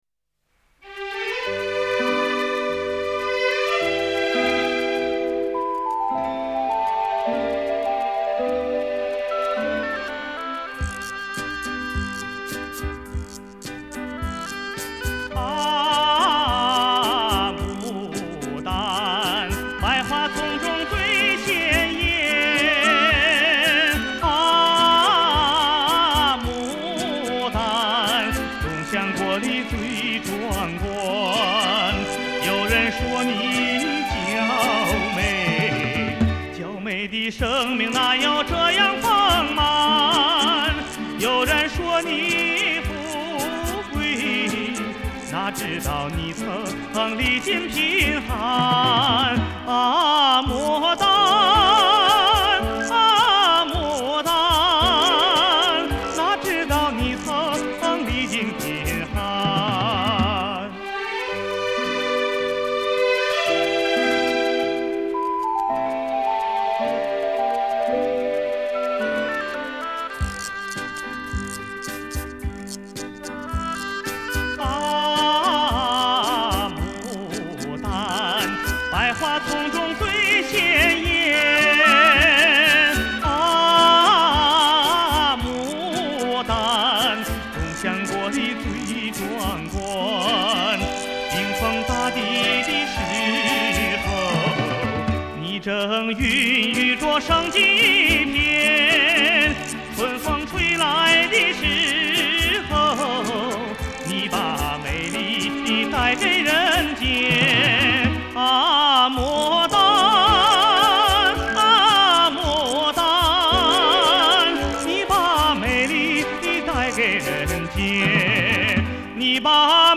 他的演唱甜美，音色明亮，感情真挚。